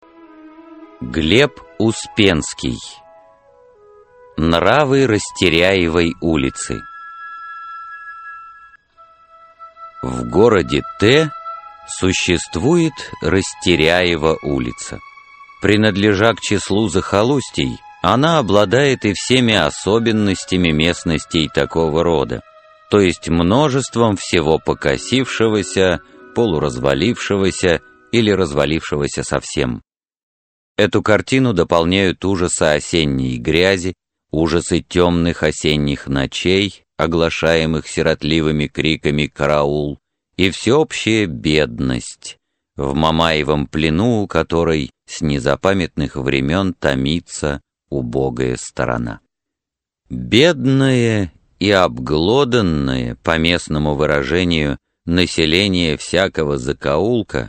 Аудиокнига Нравы Растеряевой улицы | Библиотека аудиокниг